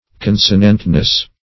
Search Result for " consonantness" : The Collaborative International Dictionary of English v.0.48: Consonantness \Con"so*nant*ness\, n. The quality or condition of being consonant, agreeable, or consistent.